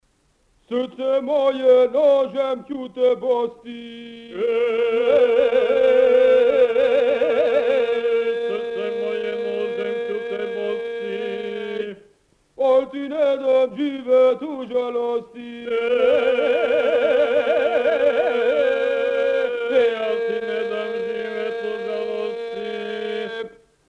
Место: Западна Босна и Далмација
– Грокталица, Западна Босна и Далмација.